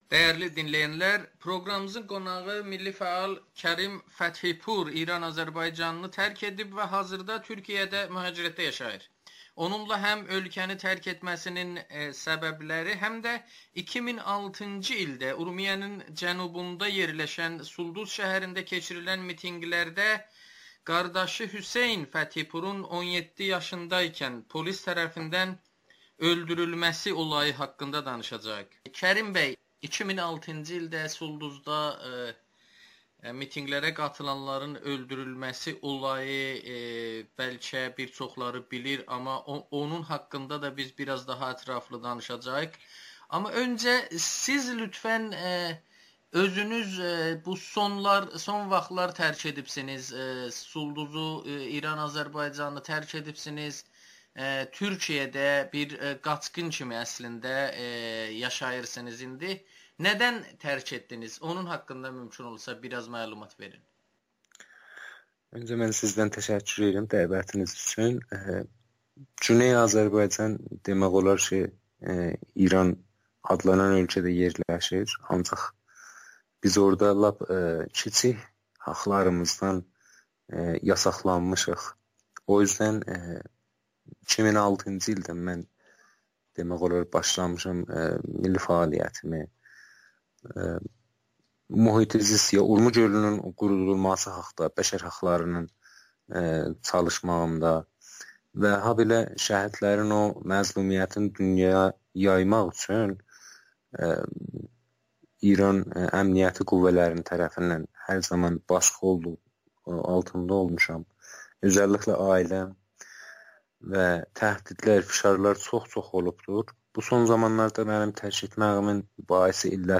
Sulduzda əzizlərimizi vəhşicəsinə öldürdülər [Audio-Müsahibə]